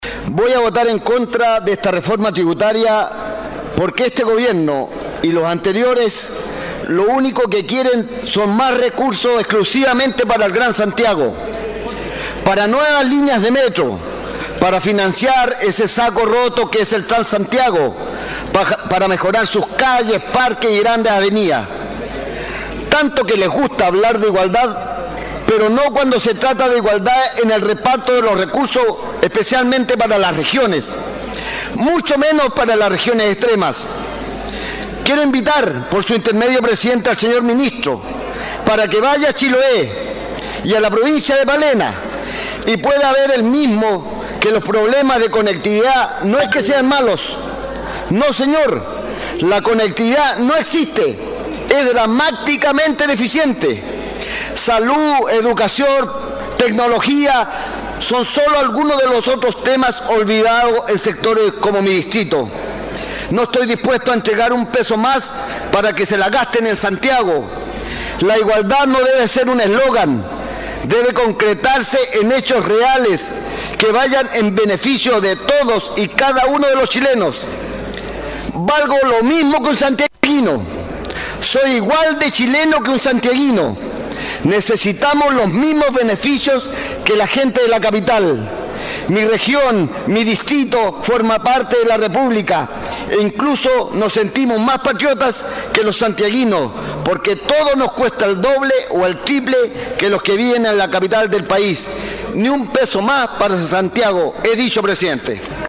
En su intervención el legislador señaló que: “lo único que se busca con esta reforma son más recursos para el gran Santiago”: